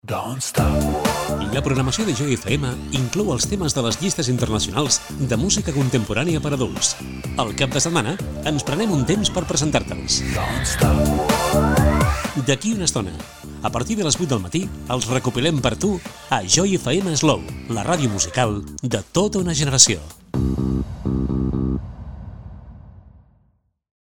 Anunci de l'inici del Top 50 quan siguin les 8 del matí